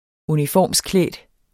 Udtale [ -ˌklεˀd ]